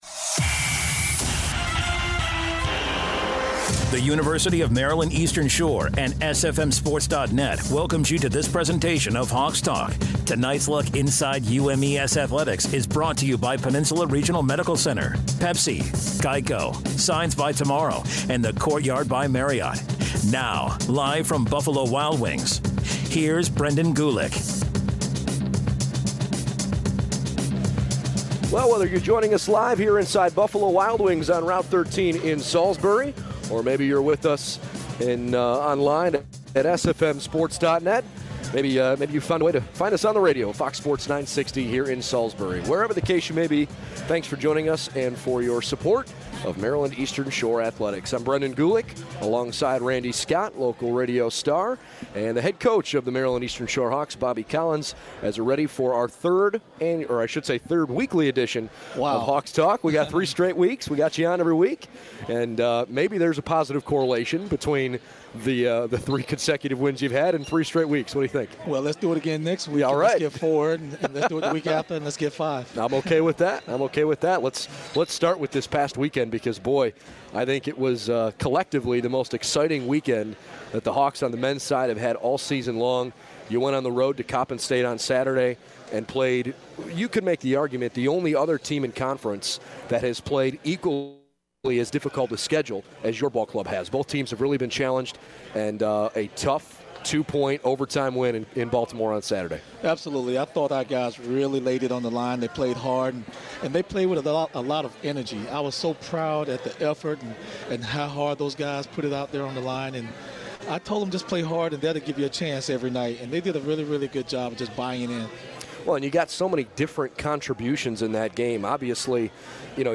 Hawks Talk is live every Wednesday night at 7pm!